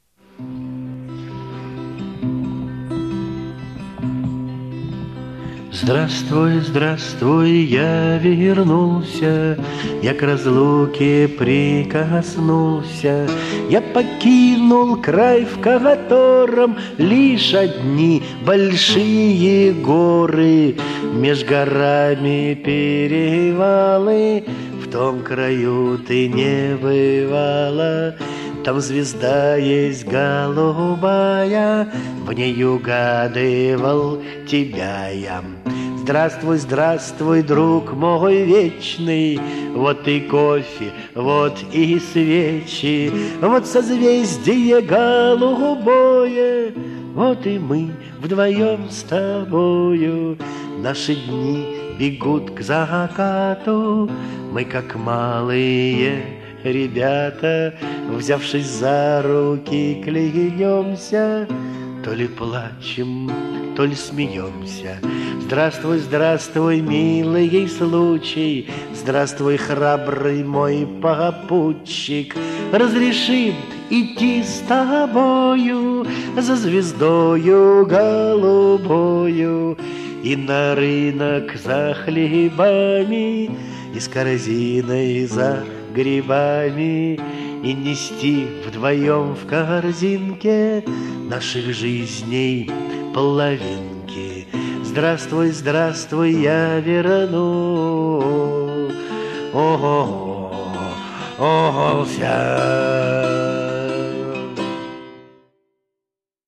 В исполнении автора